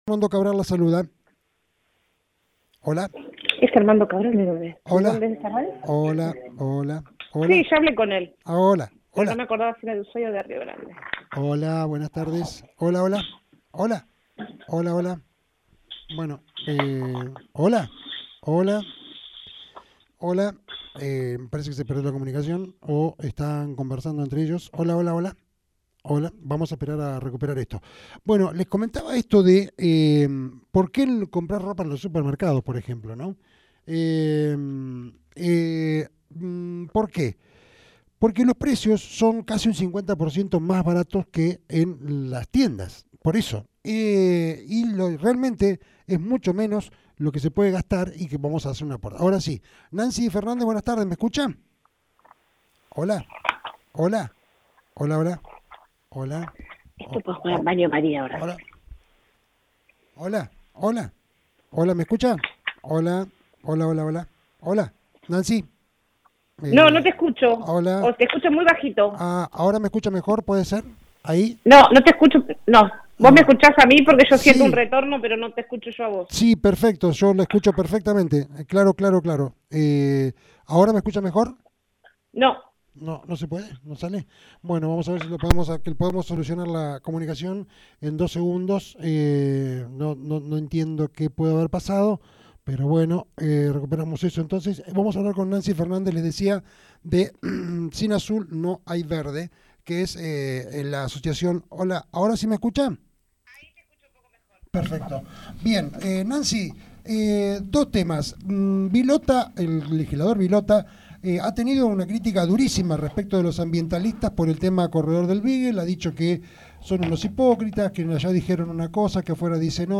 en dialogo con Reporte Diario